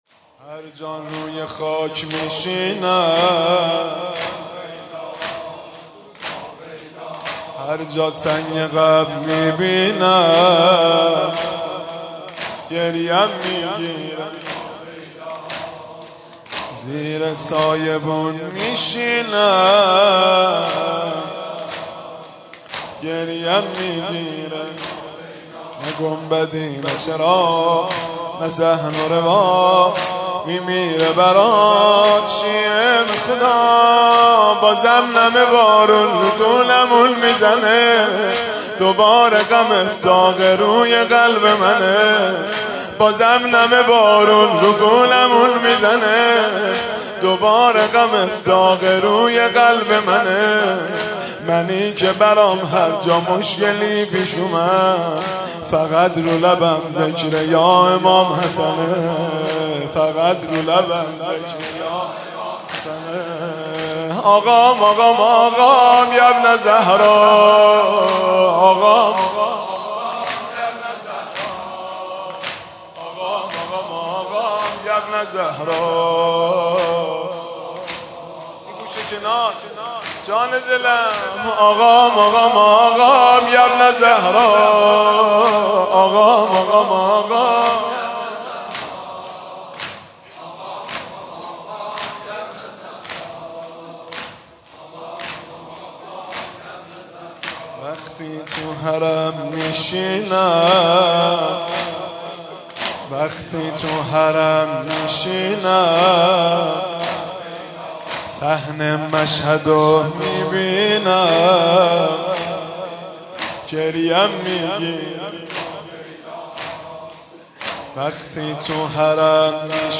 هیت ثارالله زنجان
شعرخوانی و روضه